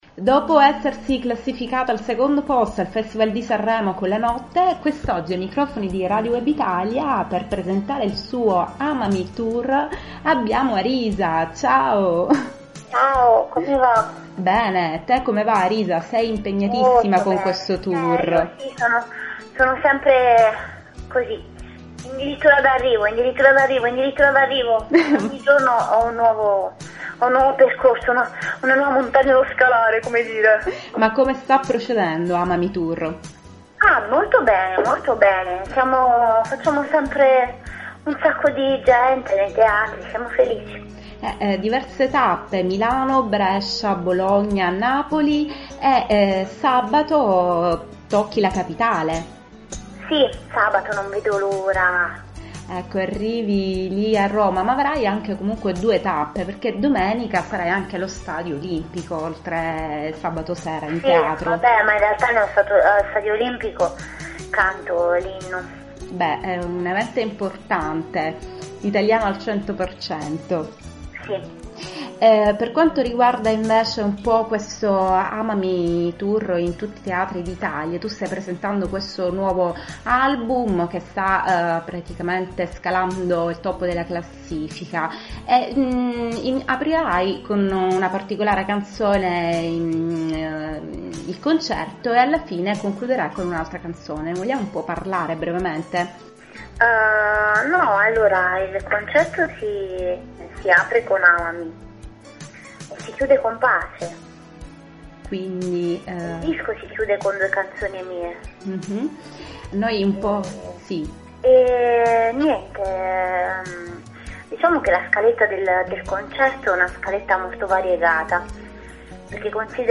Arisa-Intervista-1.mp3